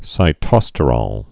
(sī-tŏstə-rôl, -rōl, sĭ-)